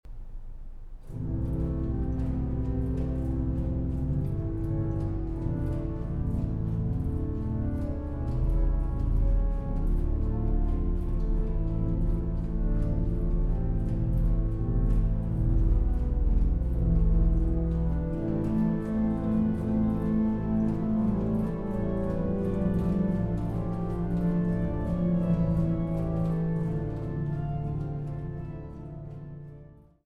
Merklin-Orgel (1877), Temple-Neuf